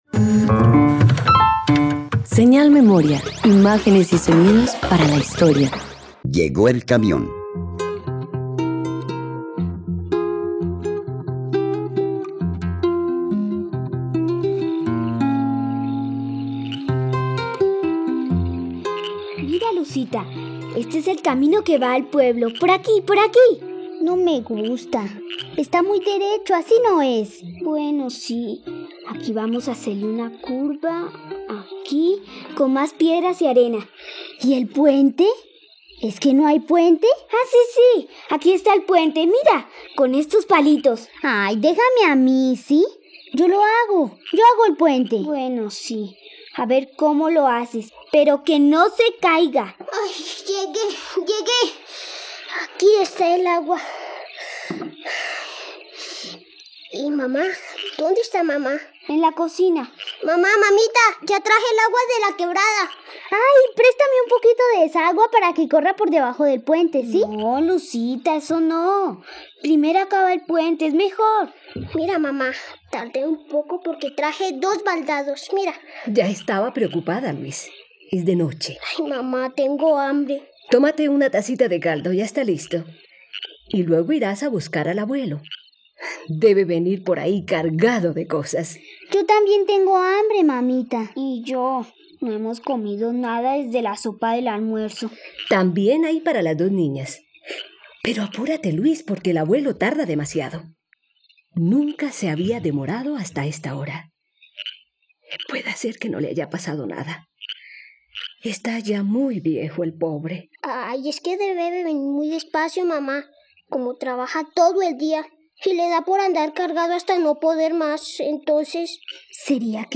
Llegó el camión - Radioteatro dominical | RTVCPlay